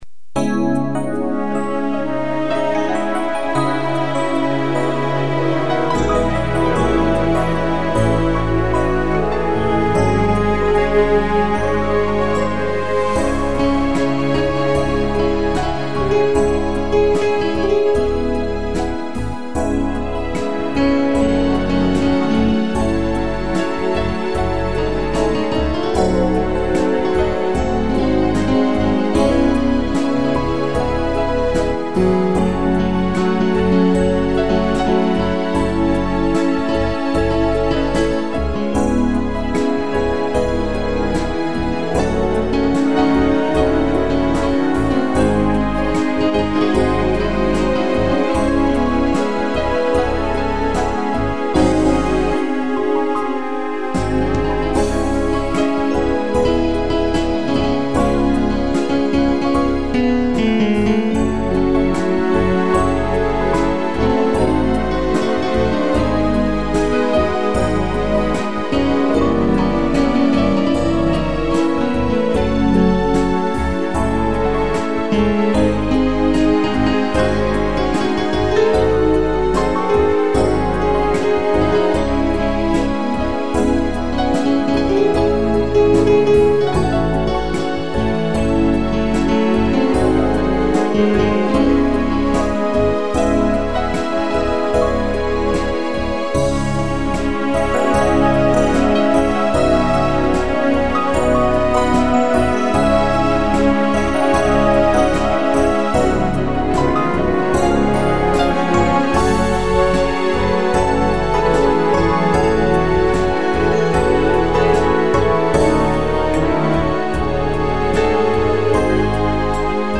piano e tutti
(instrumental)